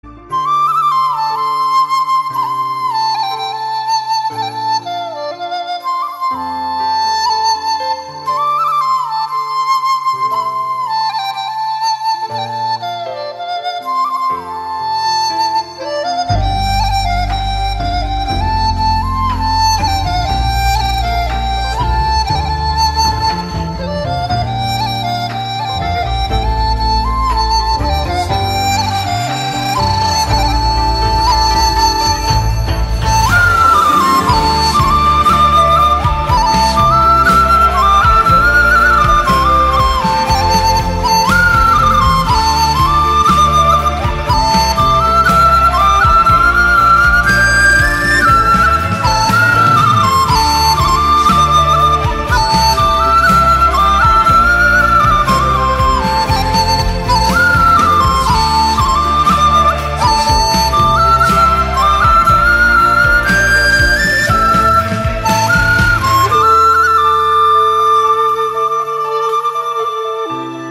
Giai điệu sáo trúc kiếm hiệp hào hùng, da diết.